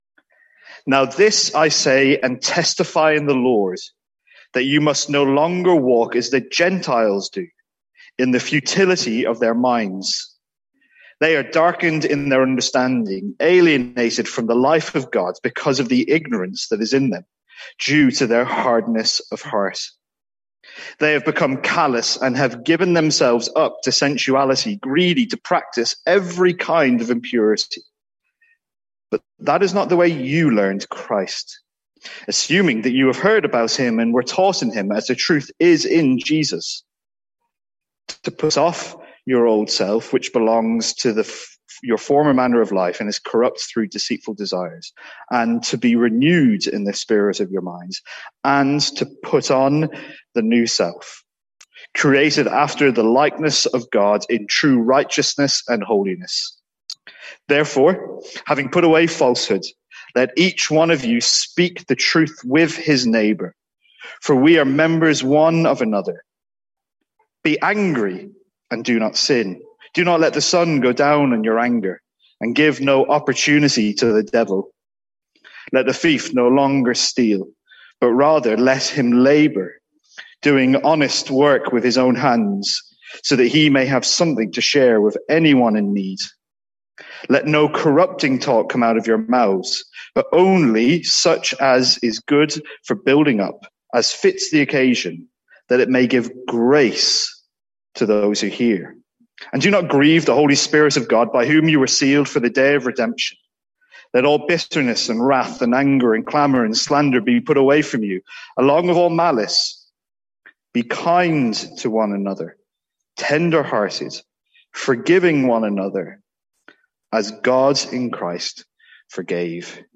Sermons | St Andrews Free Church
From our morning series in Ephesians.